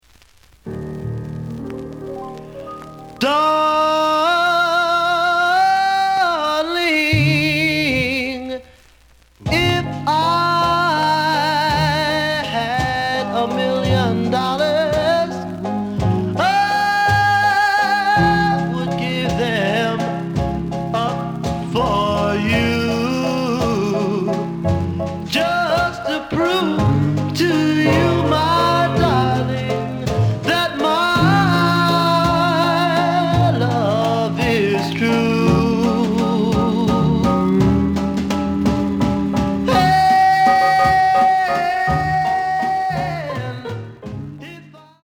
The listen sample is recorded from the actual item.
●Genre: Rhythm And Blues / Rock 'n' Roll